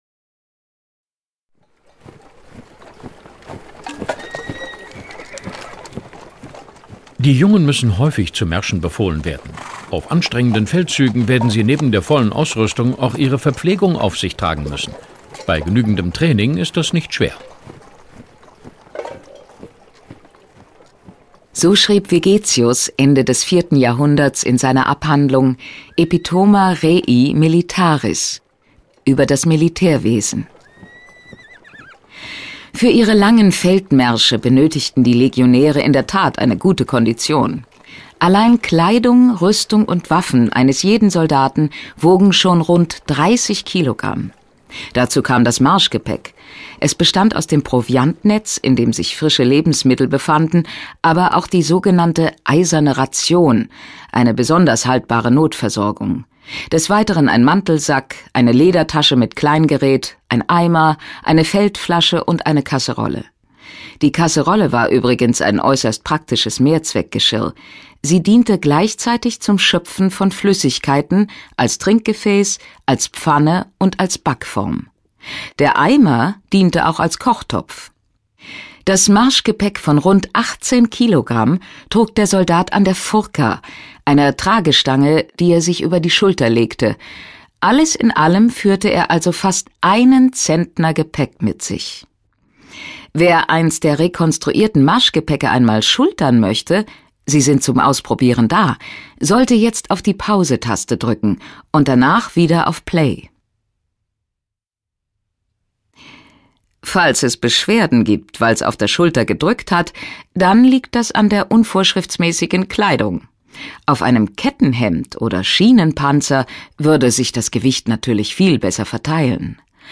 Neuer Audioguide führt Besucher durch die Dauerausstellung
Einigen Besuchern werden nicht nur Inhalte rund um die Römer in Westfalen bekannt vorkommen, sondern auch die Stimmen, die sie neben Musik, Marsch- und anderen Geräuschen hören: Unweigerlich denkt man an die Schauspieler Whoopi Goldberg, Ben Kingsley oder Liam Neeson (Schindlers Liste).